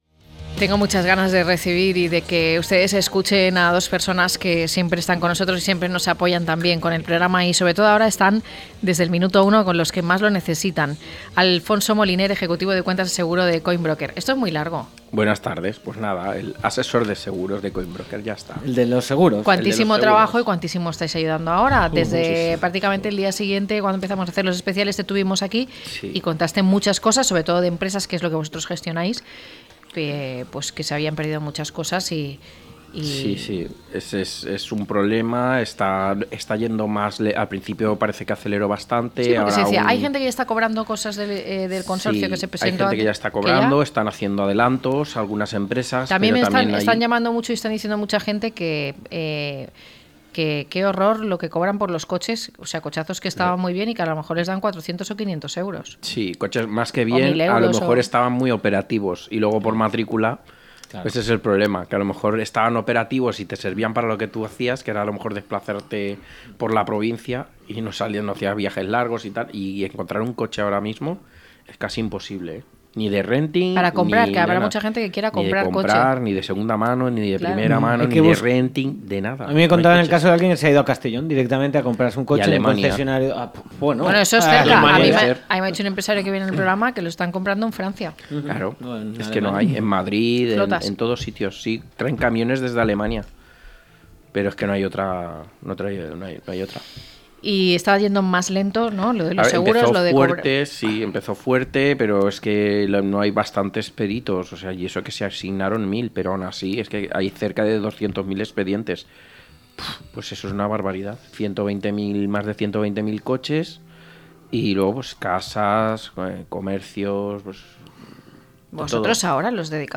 1125-LTCM-TERTULIA.mp3